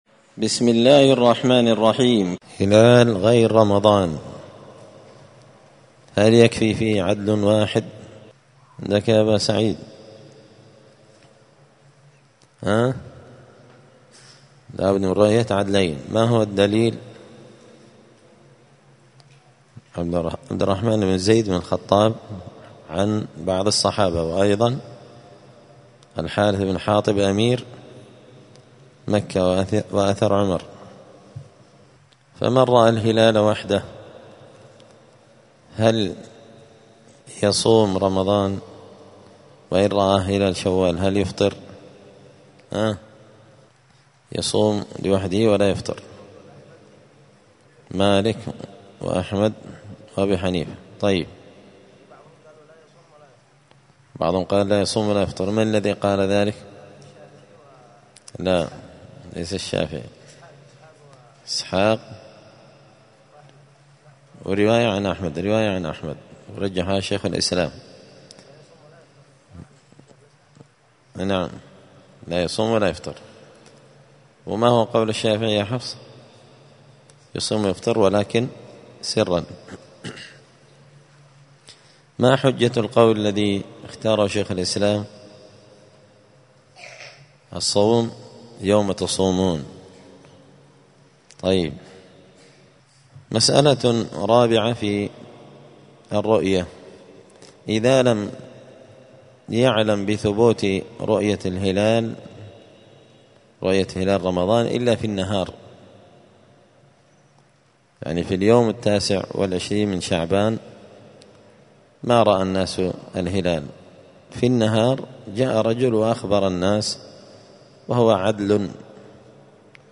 دار الحديث السلفية بمسجد الفرقان بقشن المهرة اليمن
*الدرس الخامس (5) {حكم صيام وفطر من لم يتمكن من رؤية الهلال…}*